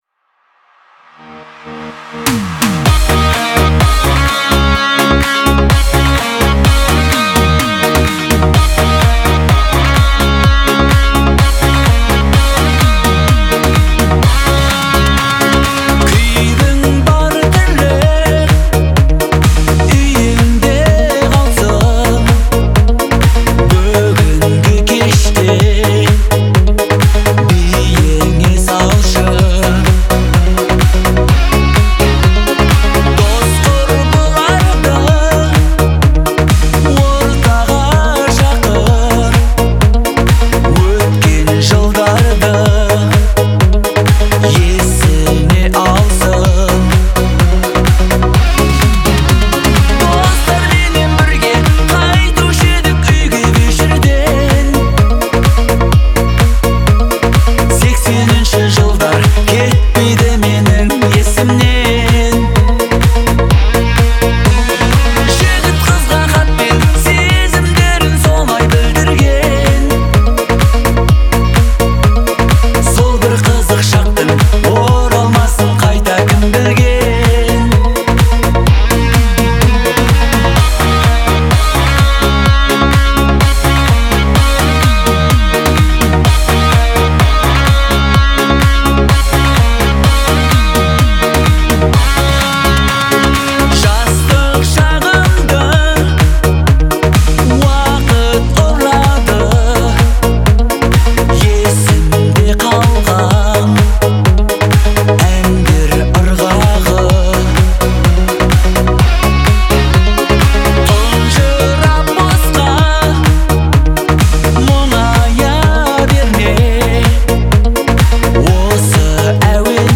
это яркий представитель казахской поп-музыки